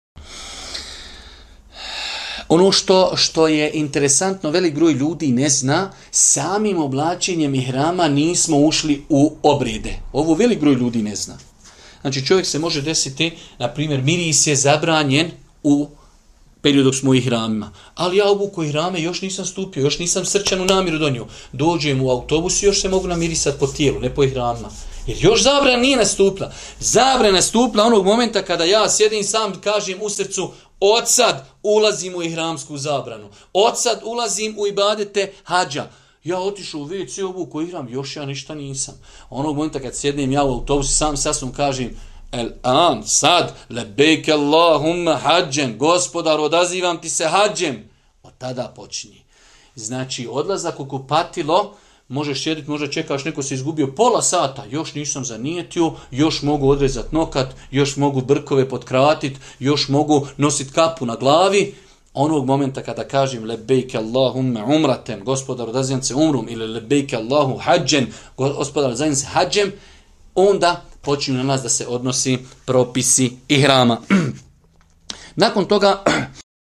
Audio mp3 odgovor